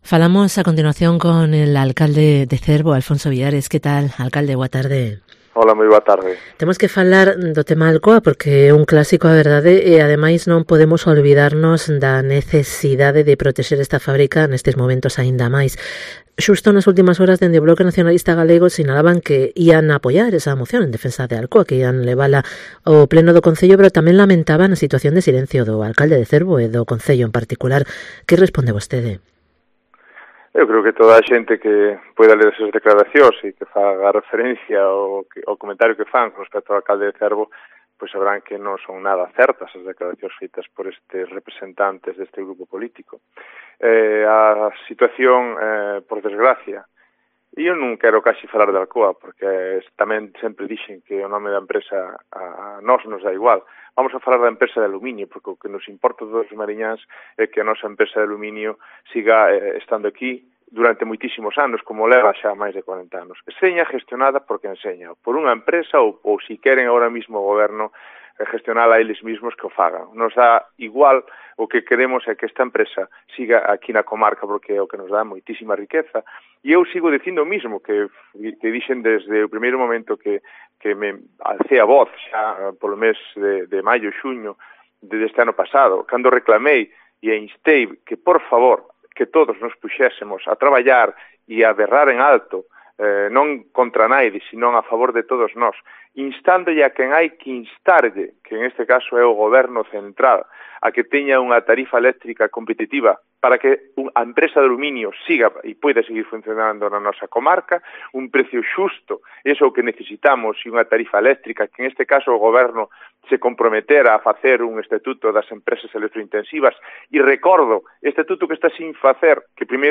Entrevista con ALFONSO VILLARES, alcalde de Cervo